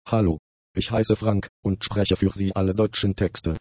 eSpeak text-to-speech project